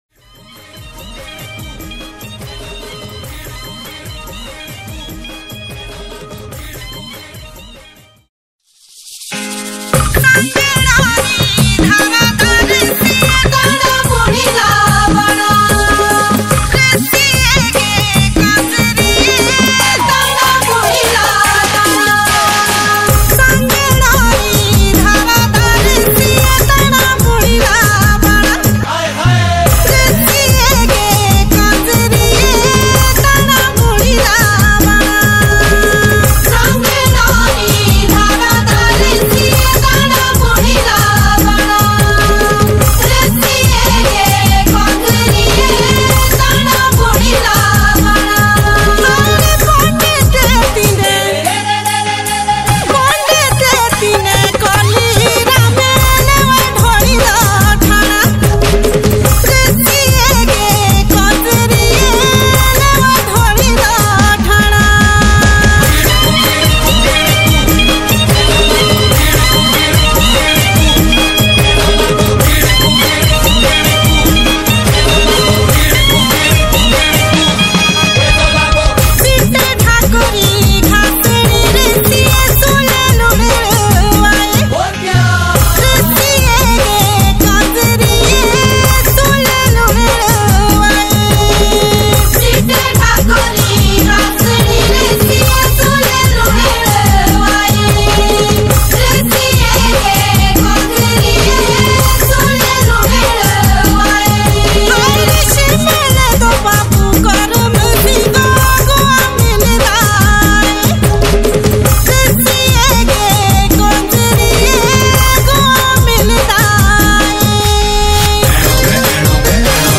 Himachali Songs